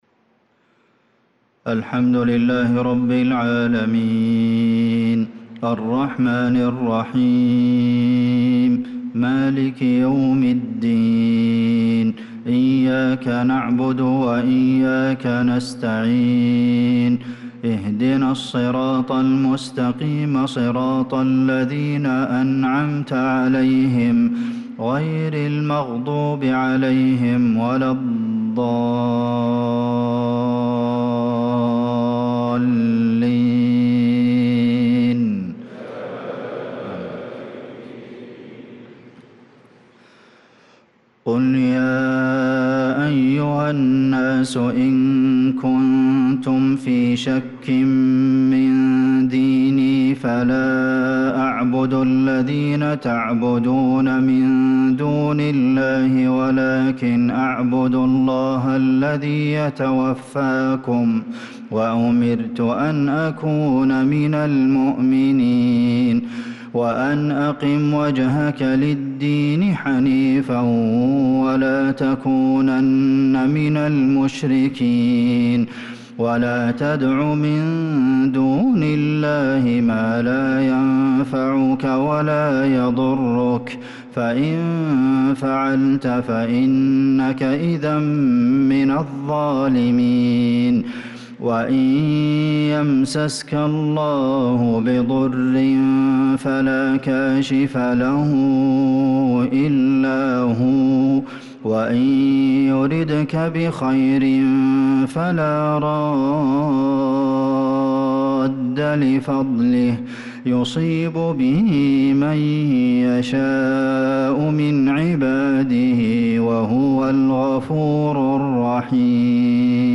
صلاة المغرب للقارئ عبدالمحسن القاسم 14 ذو الحجة 1445 هـ